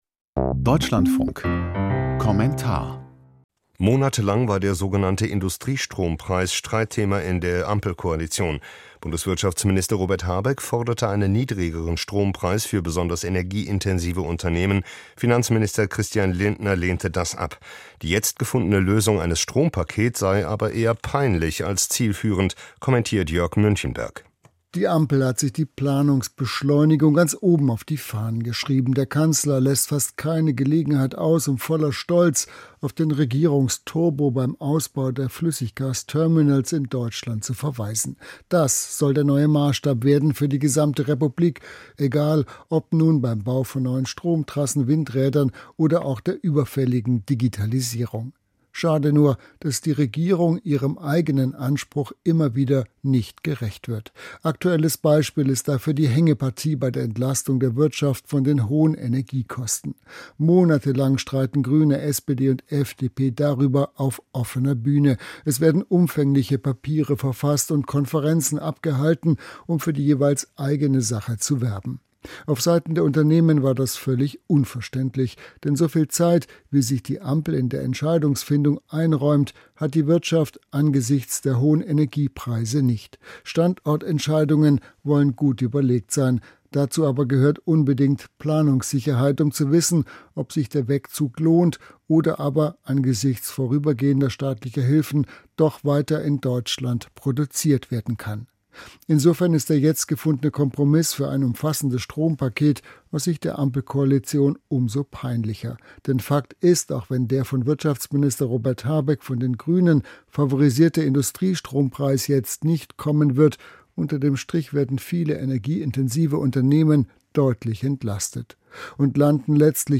Kommentar zum "Strompaket" der Ampelkoalition